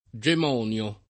[ J em 0 n L o ]